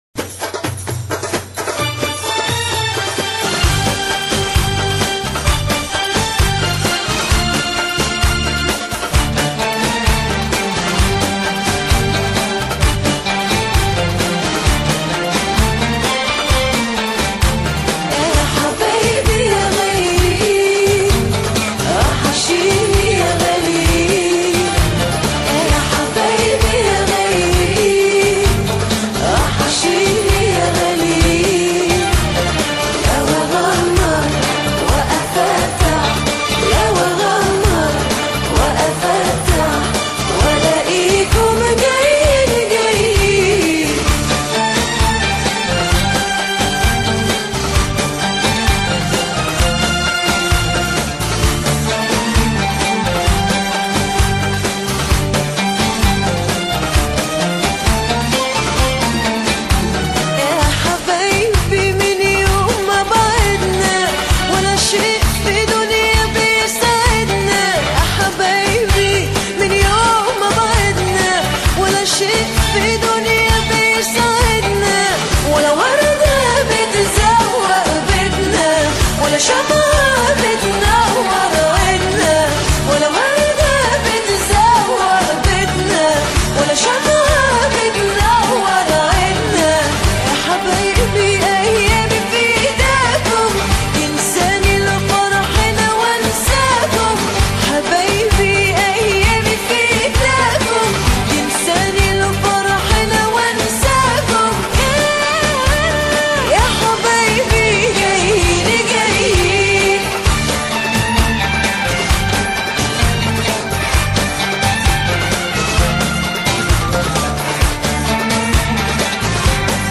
Arabskaya_muzyka___BarabanyMP3_128K.mp3